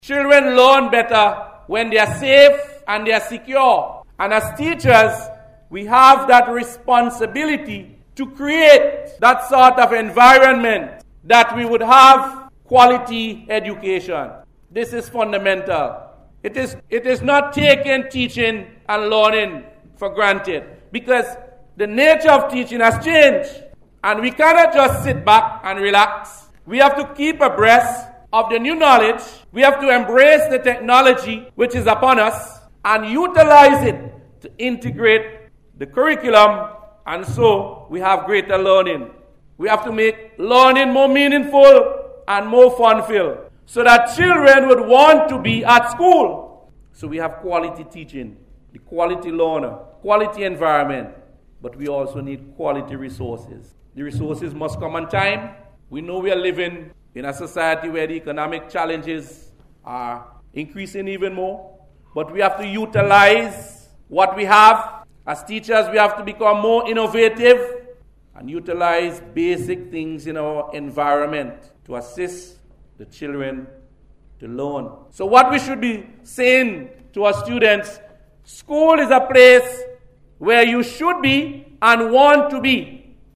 He made this statement during Monday’s opening ceremony of a two week training workshop for Early Childhood Educators.